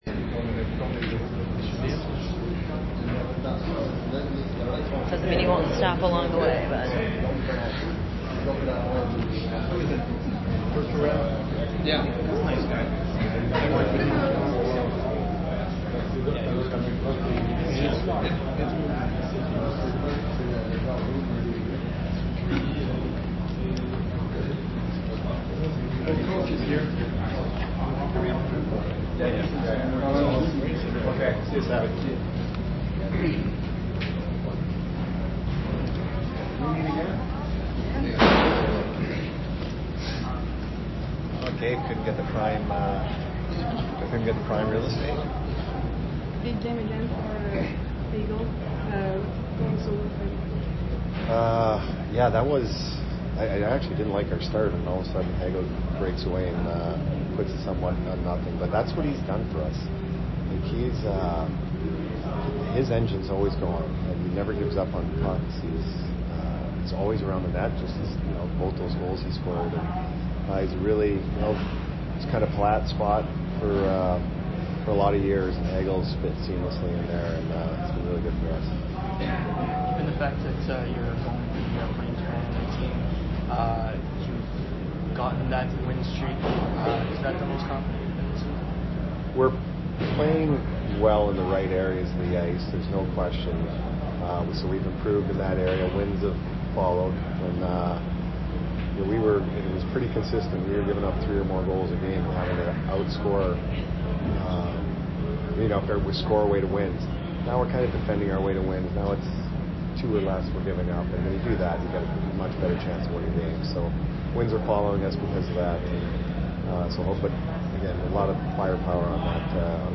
Head Coach Jon Cooper Post Game 12/17/22 at MTL